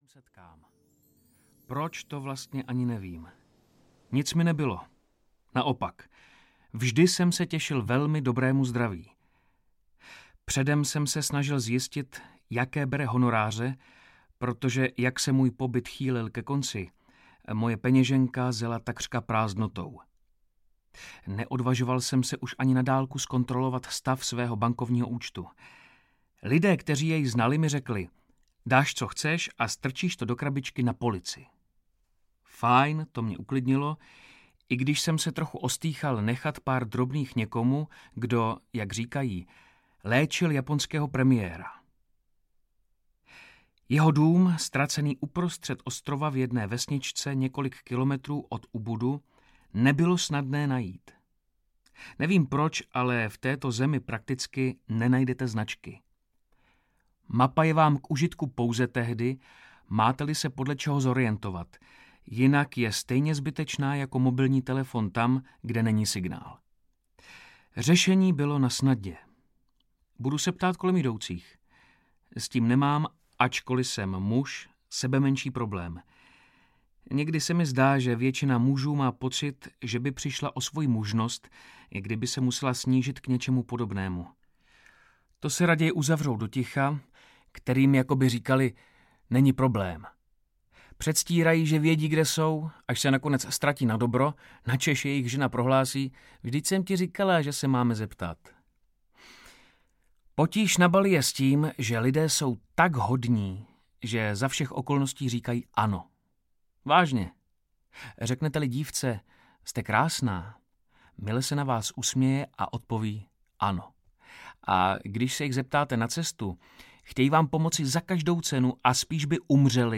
Muž, který chtěl být šťastný audiokniha
Ukázka z knihy